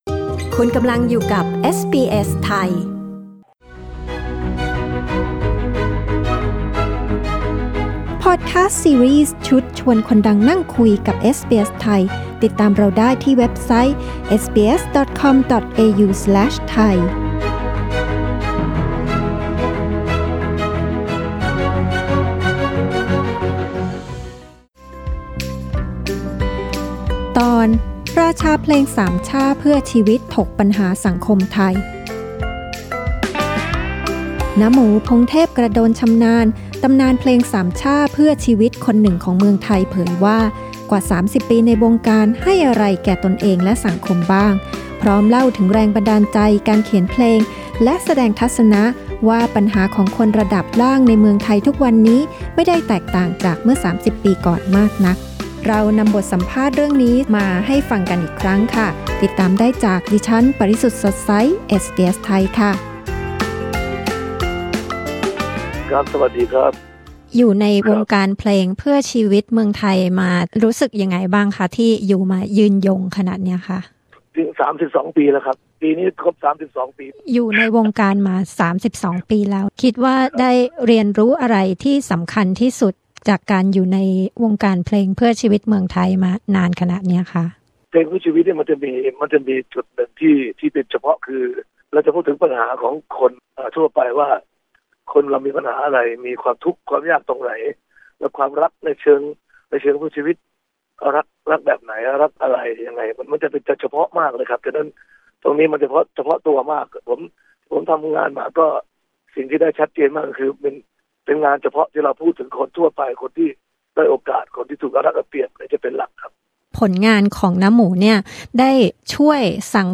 บทสัมภาษณ์นี้เผยแพร่ในรายการเอสบีเอส ไทย ครั้งแรกเมื่อ 23 มิ.ย. 2015